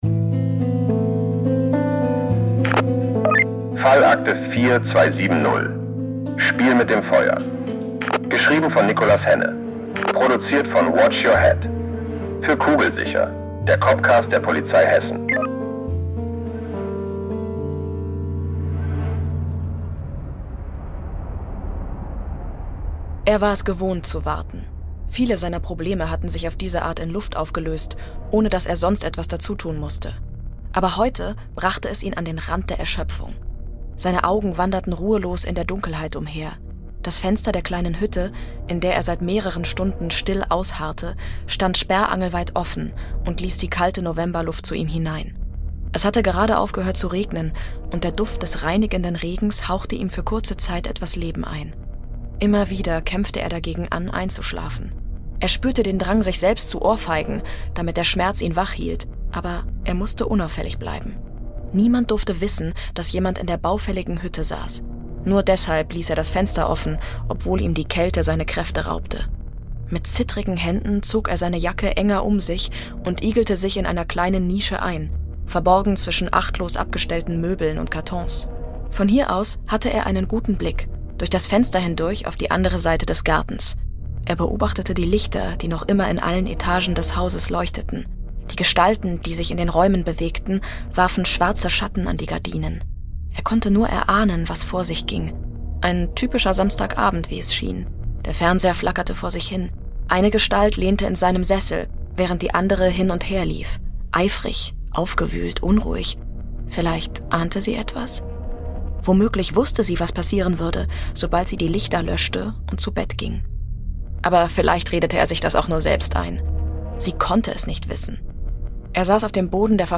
🔥 Der Hör-Krimi geht weiter!